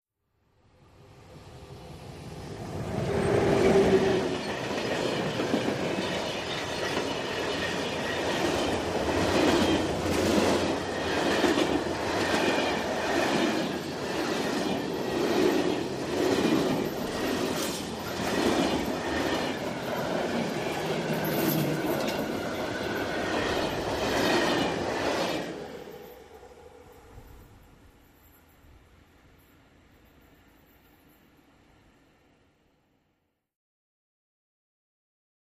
Electric Train ( European ), By Very Fast, Cu.